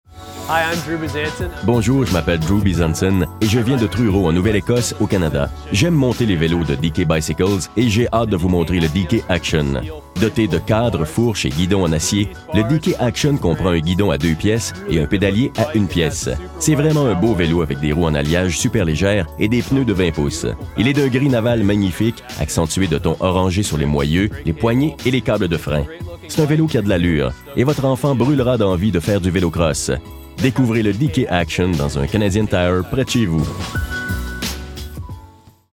SURIMPRESSION VOCALE :